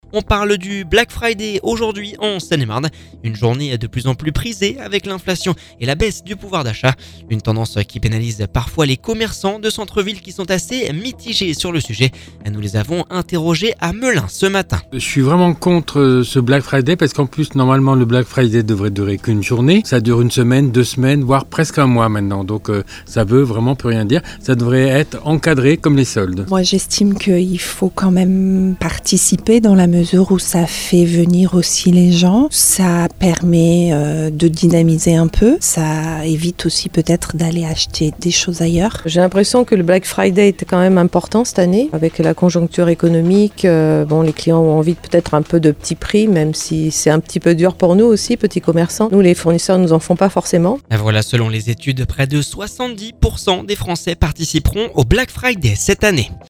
Nous les avons interrogés à Melun ce matin…